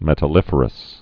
(mĕtl-ĭfər-əs)